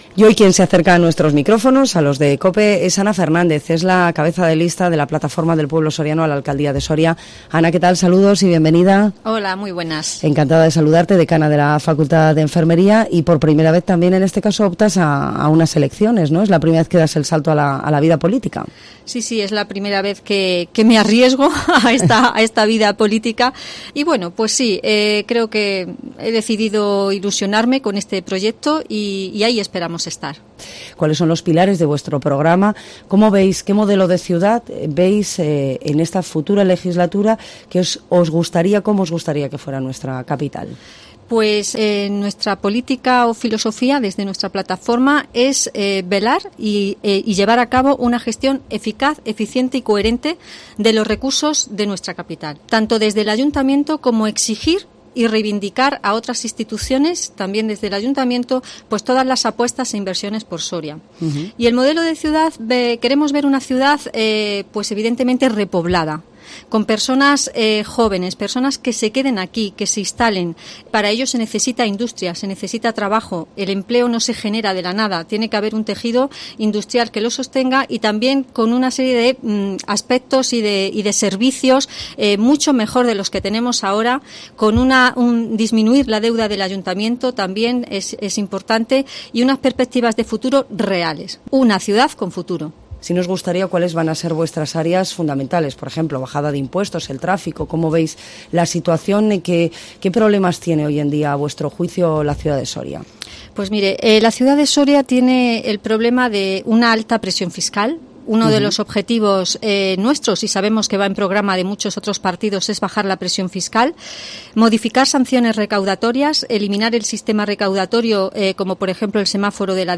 Entrevista PPSO elecciones municipales Soria